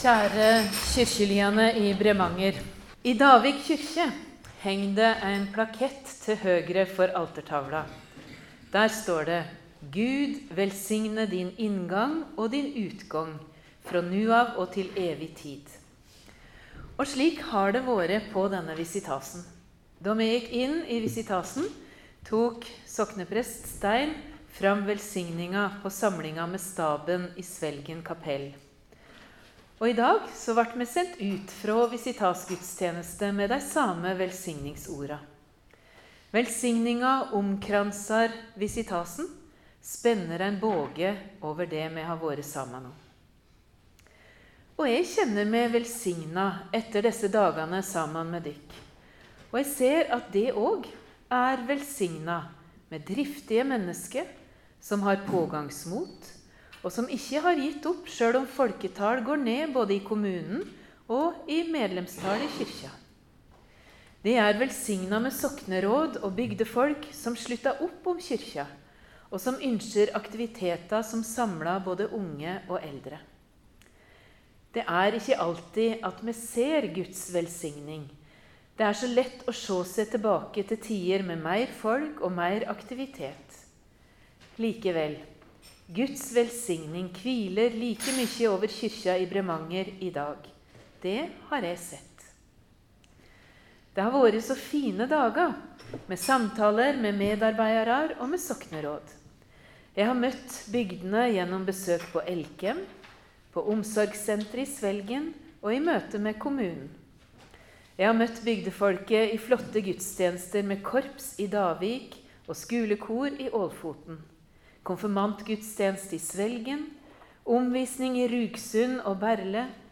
Biskop Ragnhild Jepsen var på visitas i Bremanger i Nordfjord med sokna Midt-Gulen, Davik, Ålfoten, Rugsund, Berle, Frøya og Bremanger 23.-27. oktober 2024. Her finn du visitasforedraget og opptak av preika.